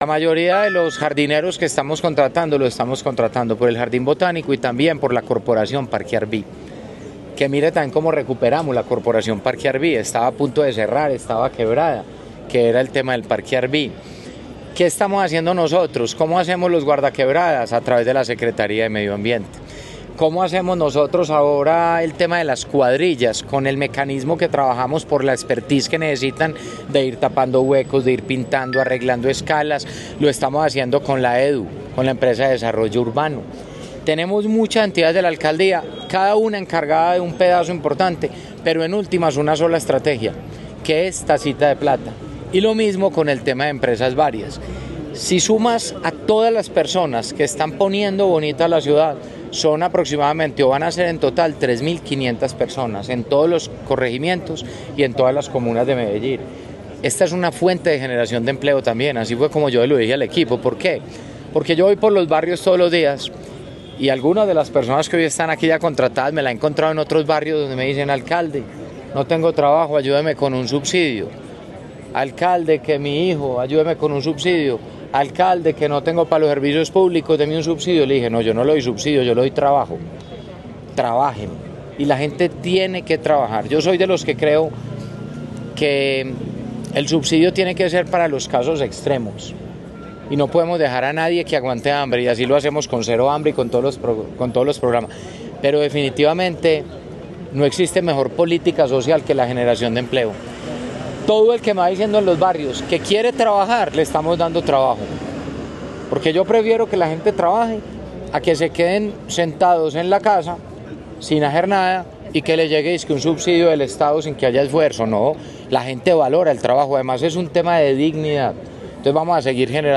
Declaraciones-del-Alcalde-Federico-Gutierrez.-Tacita-de-Plata.mp3